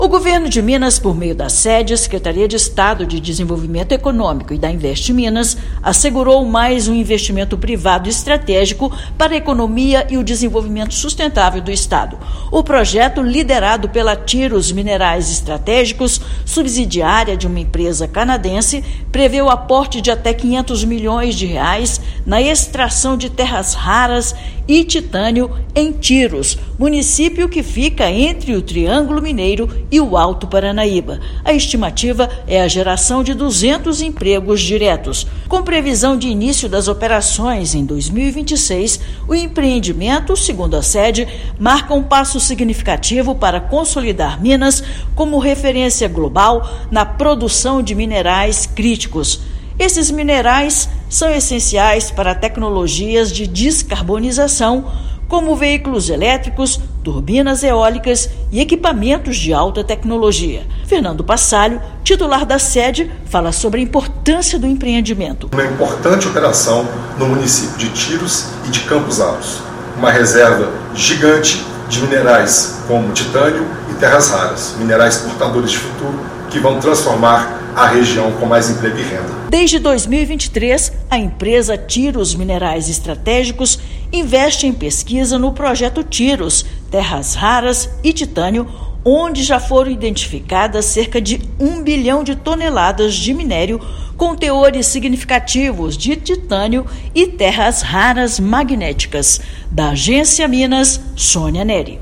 Subsidiária da companhia canadense pretende iniciar operações em 2026 com a geração de até 200 empregos diretos. Ouça matéria de rádio.